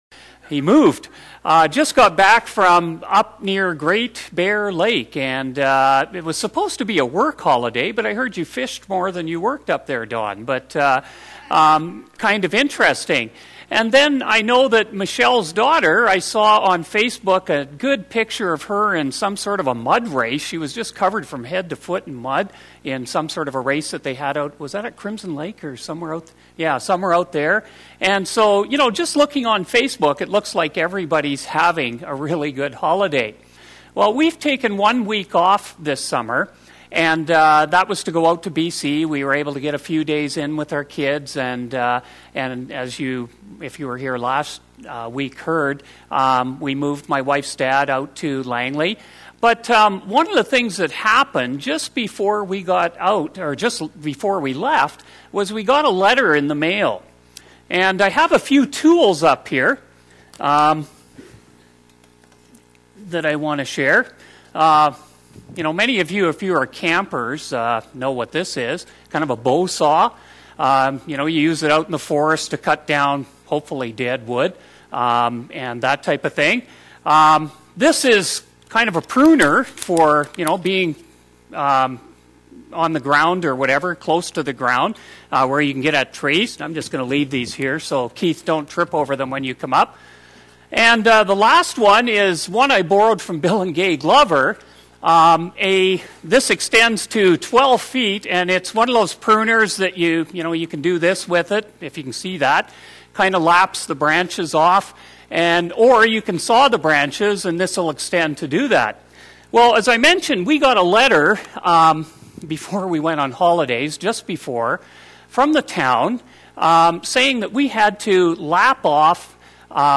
Sermons Archive - Page 54 of 58 - Rocky Mountain Alliance Church